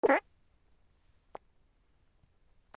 おなら
/ J｜フォーリー(布ずれ・動作) / J-25 ｜おなら・大便